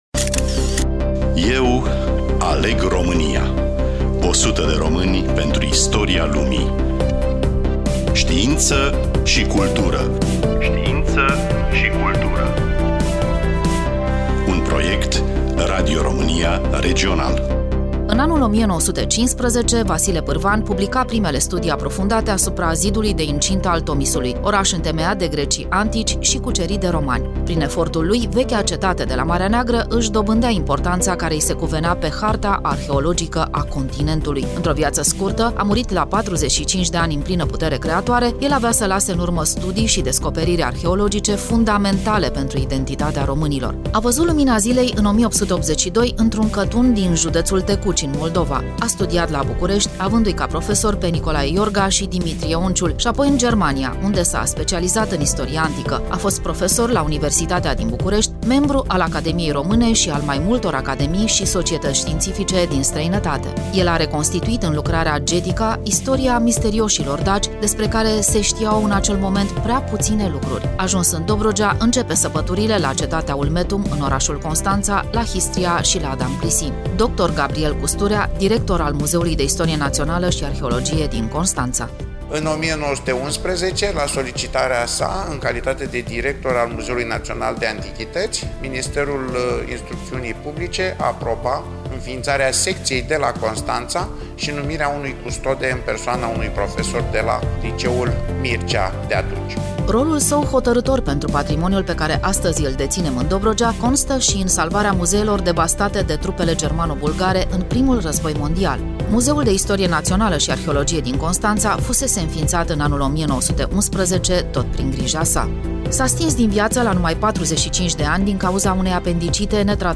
Studioul: Radio România Constanţa